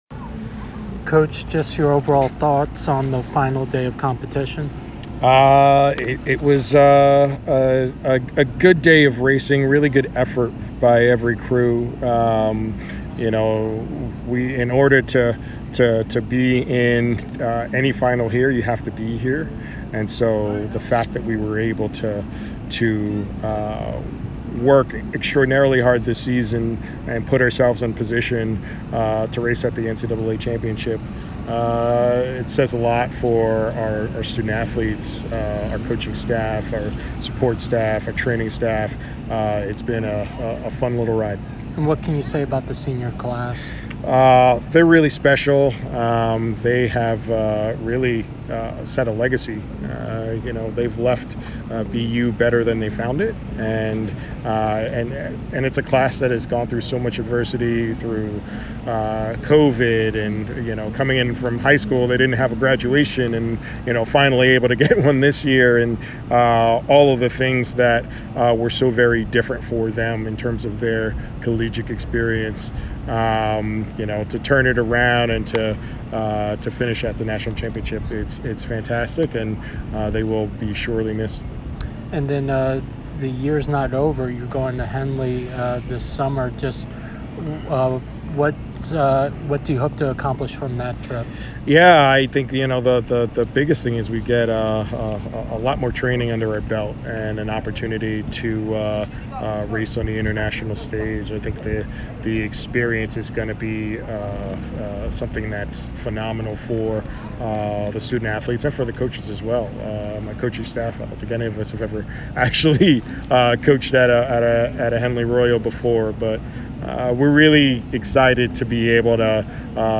NCAA Championship Day 3 Interview